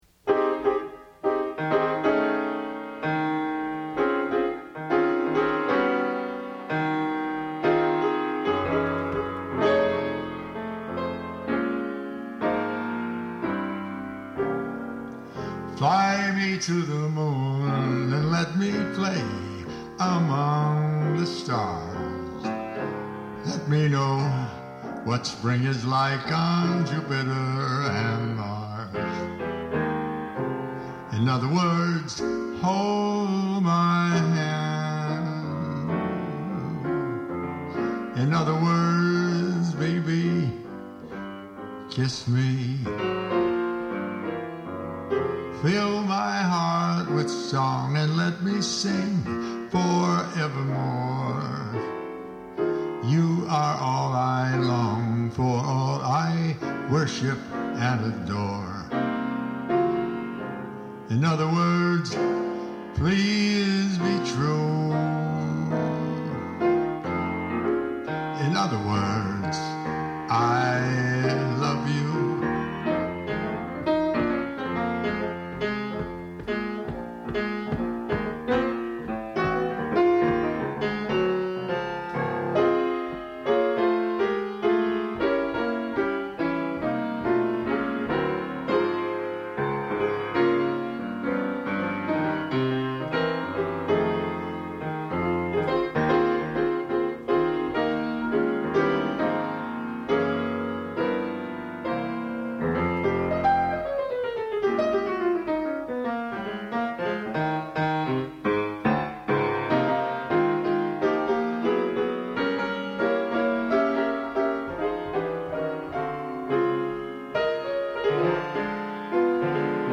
Solo Piano & Voice